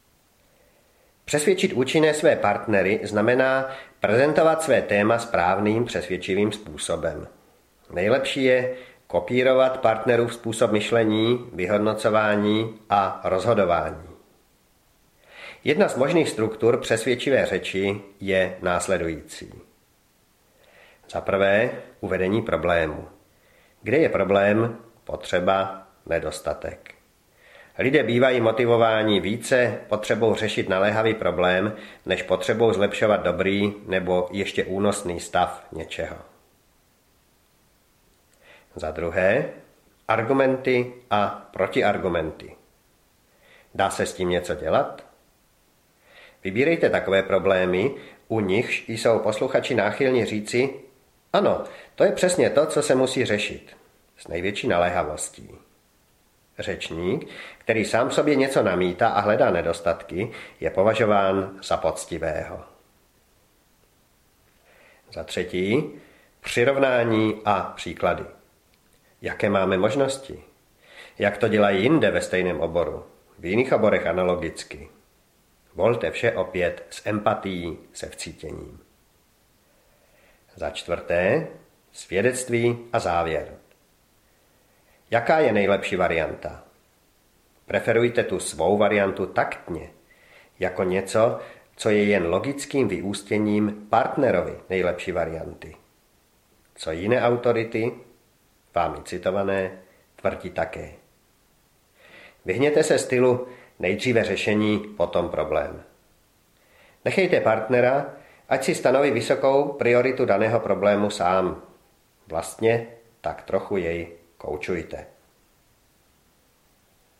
Odhalte manipulující trikaře audiokniha
Ukázka z knihy